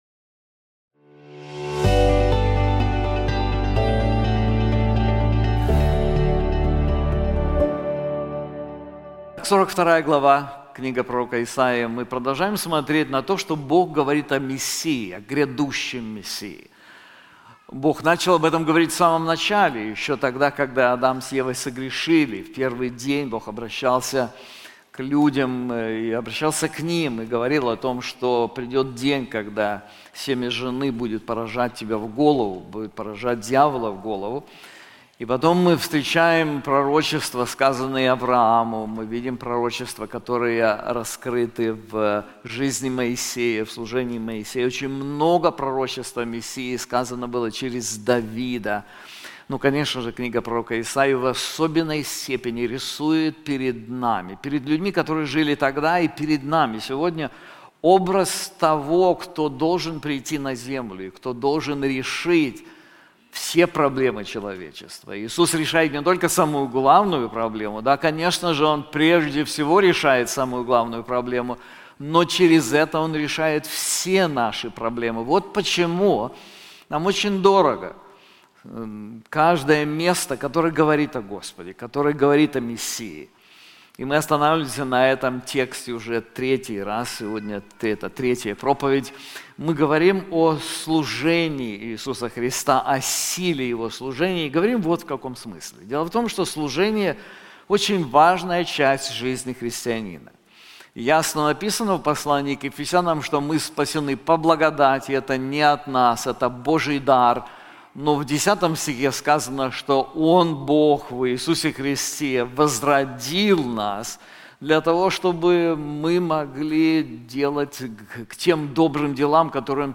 This sermon is also available in English:The Power of Christ's Ministry • Part 2 • Isaiah 42:1-28